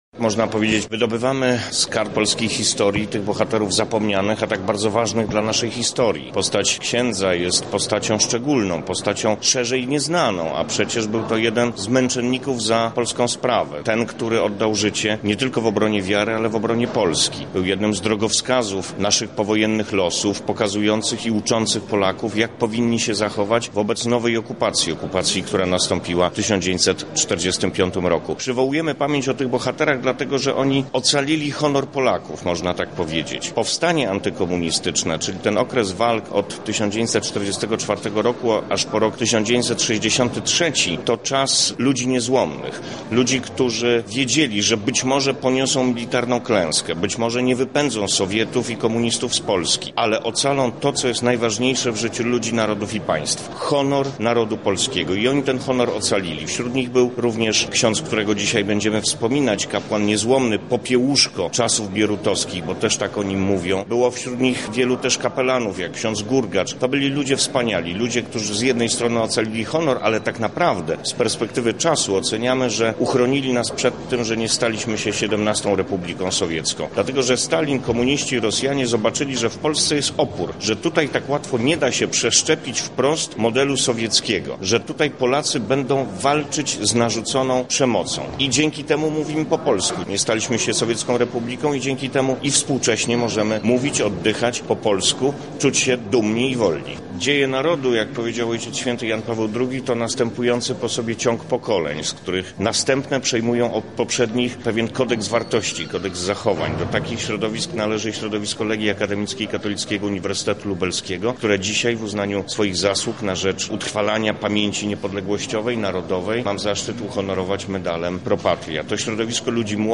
Przywołujemy pamięć o tych bohaterach, dlatego, ze ocalili oni honor Polaków. -mówi Szef Urzędu do Spraw Kombatantów i Osób Represjonowanych Jan Józef Kasprzyk